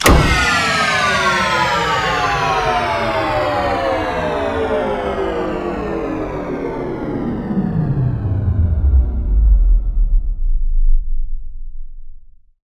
power_shutdown.wav